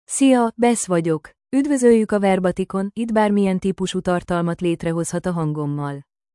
FemaleHungarian (Hungary)
BethFemale Hungarian AI voice
Voice sample
Listen to Beth's female Hungarian voice.
Female